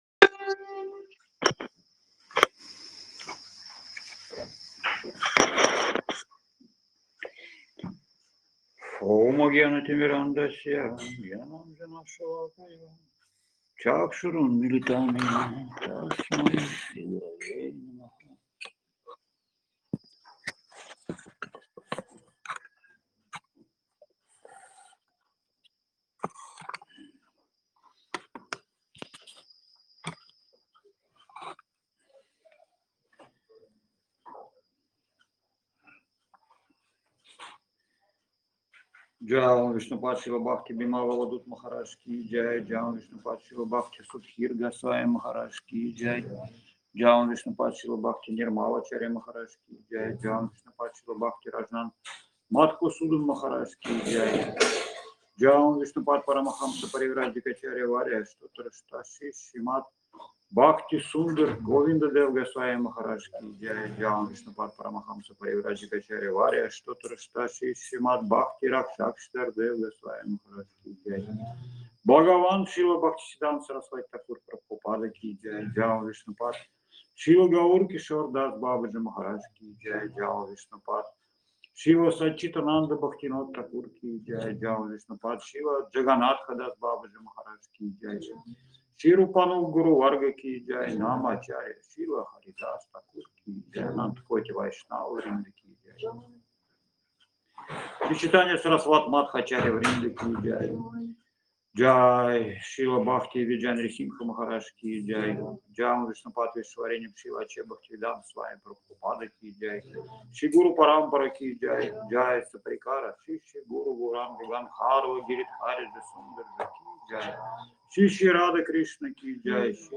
Место: Абхазия
Лекции полностью
Бхаджан